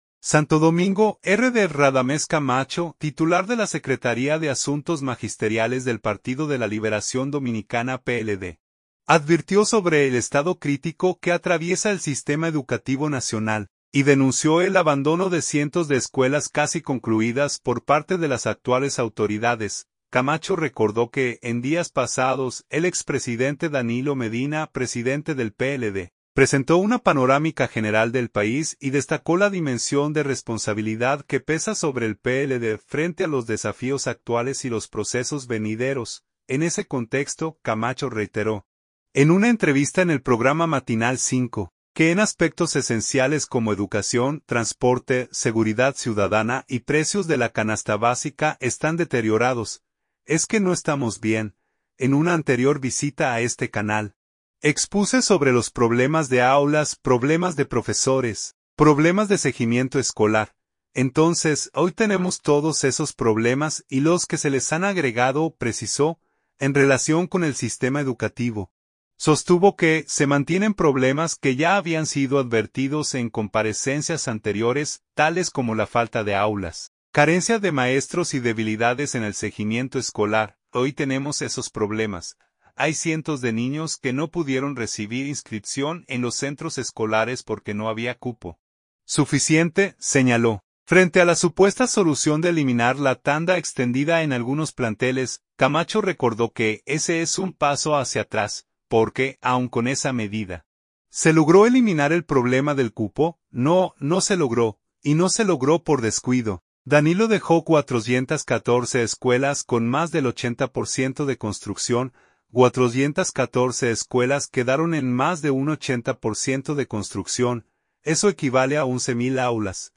en una entrevista en el programa Matinal 5